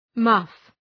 Shkrimi fonetik {mʌf}